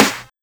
SNARE84.wav